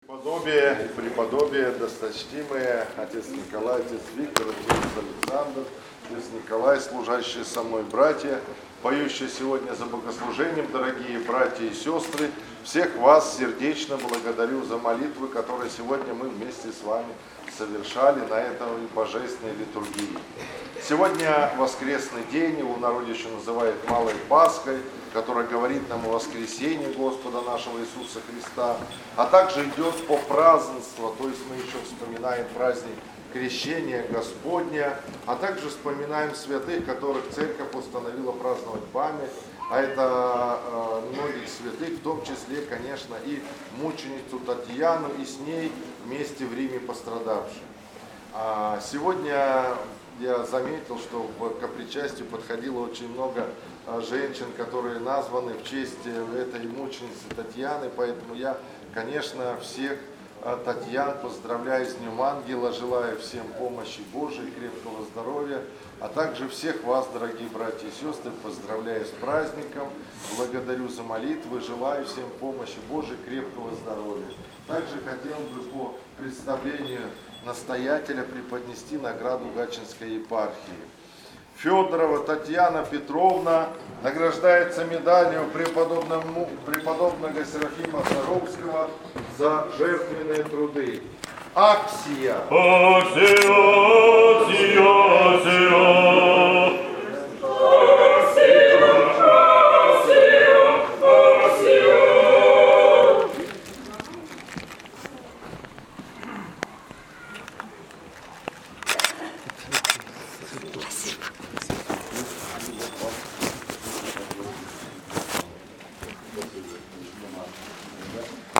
Слово епископа Митрофана после богослужения в праздник св. мц. Татианы. 25.01.2026